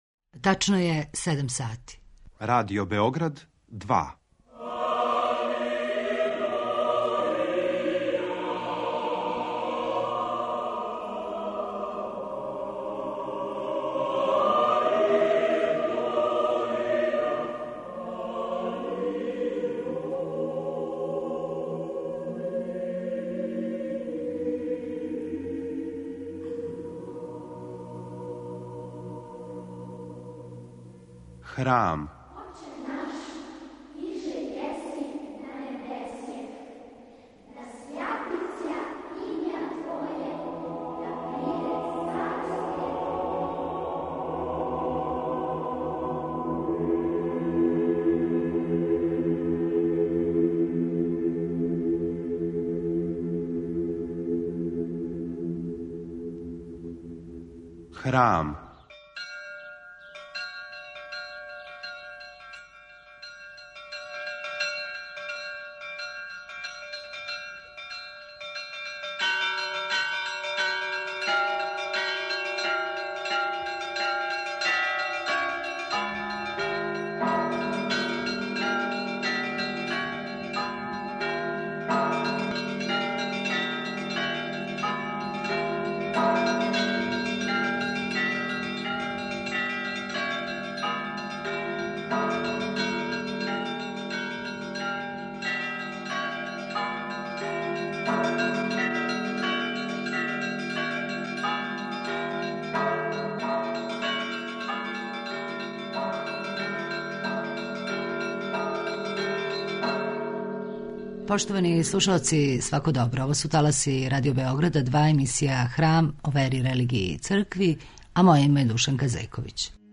Емисија о вери, религији, цркви...